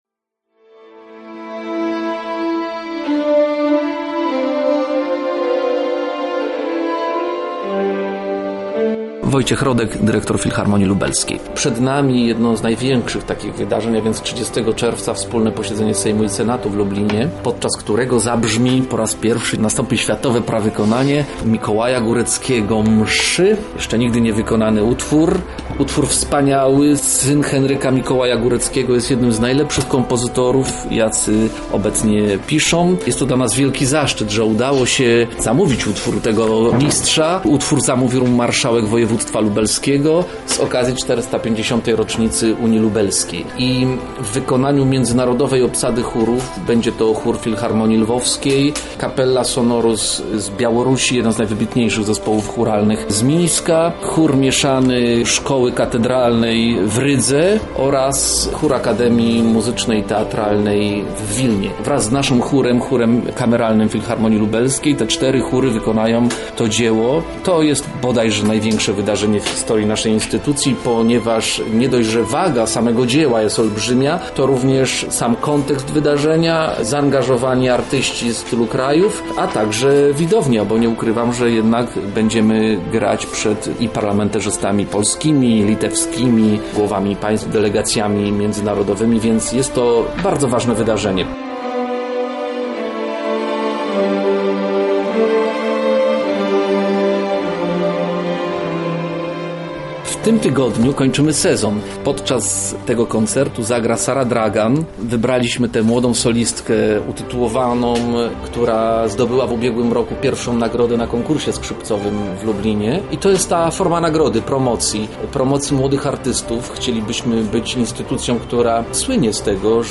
Konferencja Filharmonii Lubelskiej
Konferencja-Filharmonia_mixdown.mp3